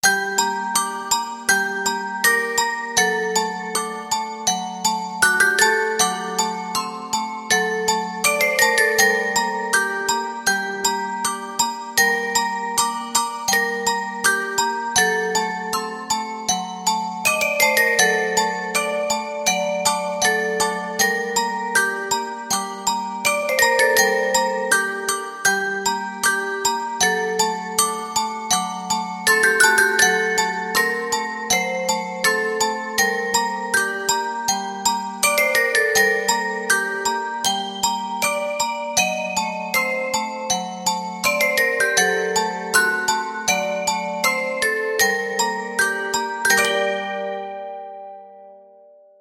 Здесь собраны лучшие композиции с нежным механическим звучанием, которые подойдут для релаксации, творчества или создания особой атмосферы.
Мелодия жуткой музыкальной шкатулки из фильма ужасов